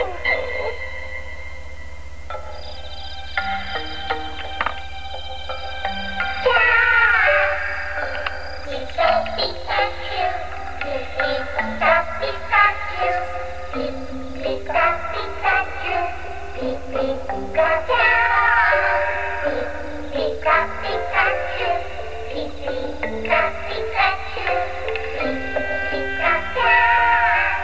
pikachant.wav